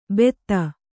♪ betta